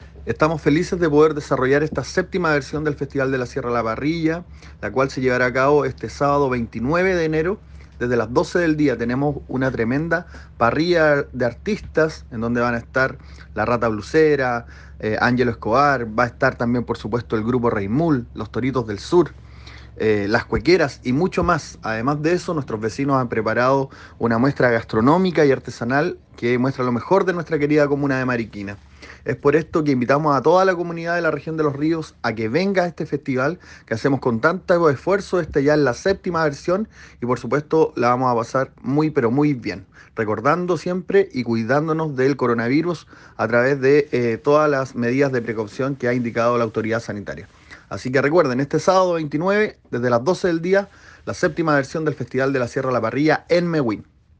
Consultado el Coordinador de la actividad Concejal Rodrigo Salazar dijo lo siguiente a pautalosríoscl